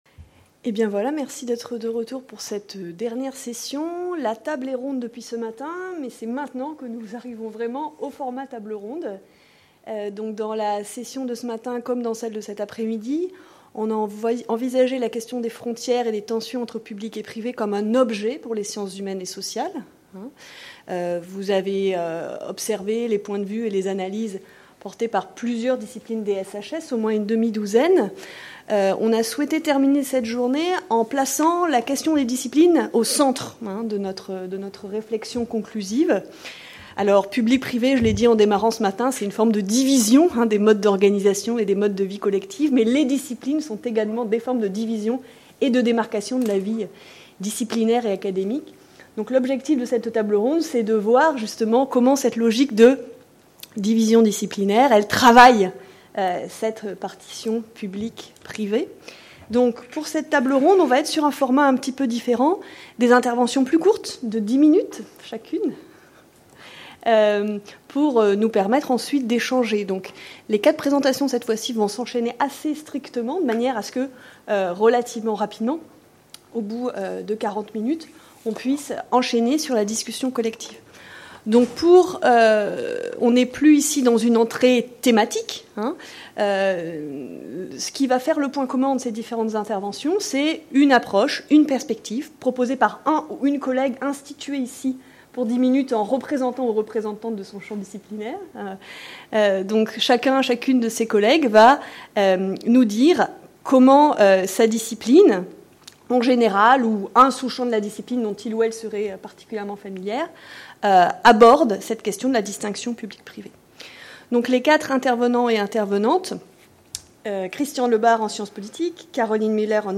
Table ronde – Les SHS et la partition public / privé | Canal U
Durant la table-ronde conclusive, des chercheur·es en histoire, économie, droit et science politique ont échangé sur la place qu’occupe la partition public / privé dans leurs disciplines respectives, afin de mettre au jour les fondements cognitifs de cette construction historique et d’esquisser leurs recompositions présentes.